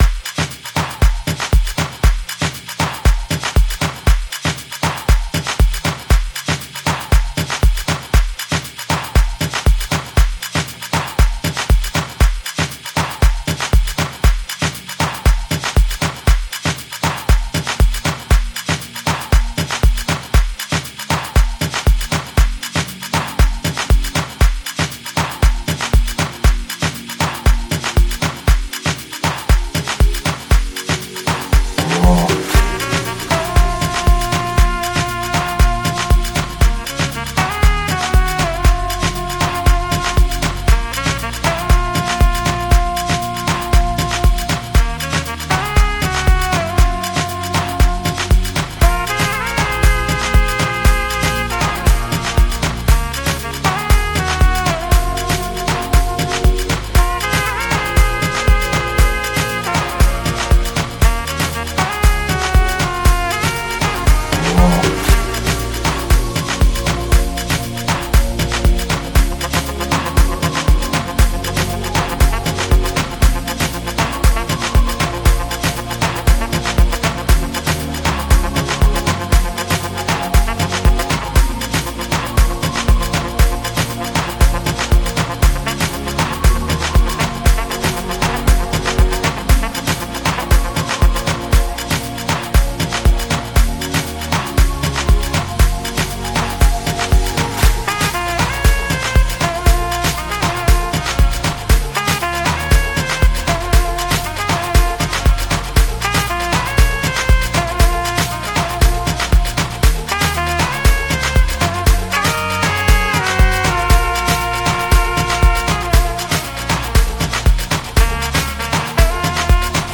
South African singer-songwriters